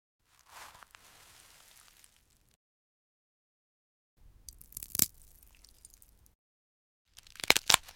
Harvesting a glass carrot in sound effects free download
Harvesting a glass carrot in extreme macro 🍃✨ The most soothing ASMR sounds you’ll hear today.